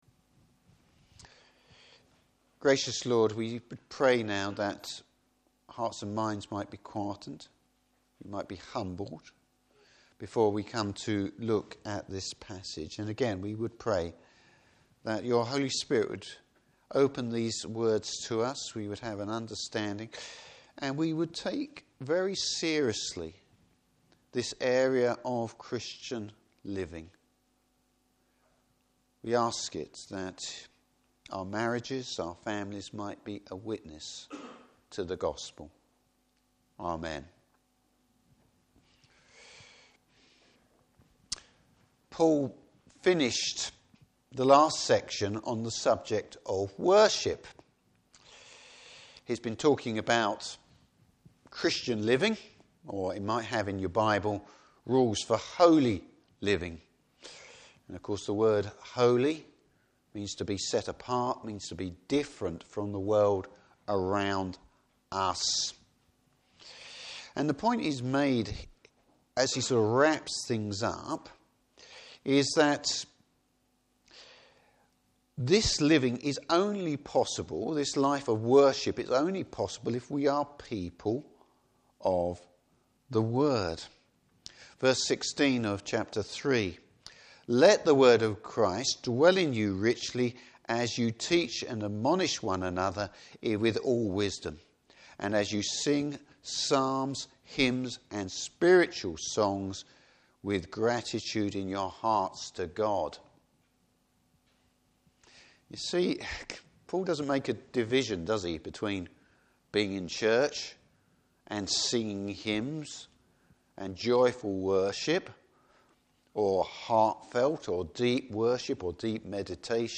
Service Type: Morning Service How does being a Christian show in the family and at work?